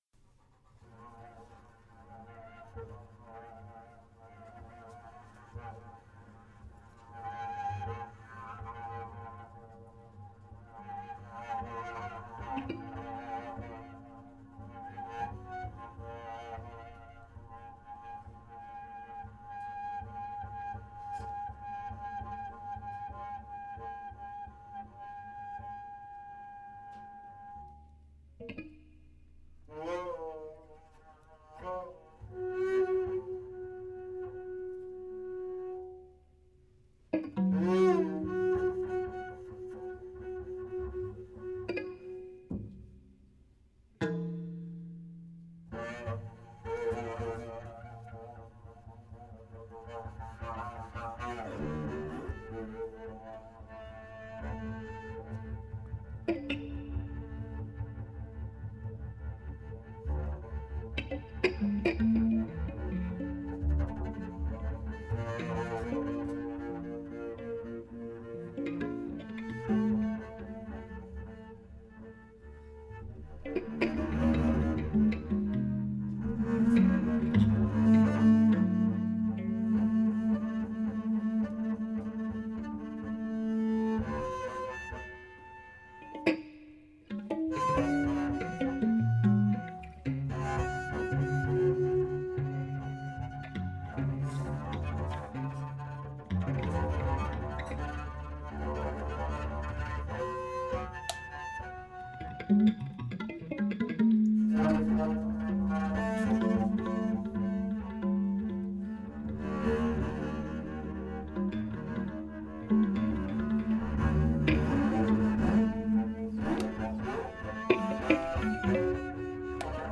electric guitar
double bass
Recorded live
at "19PaulFort", Paris, France.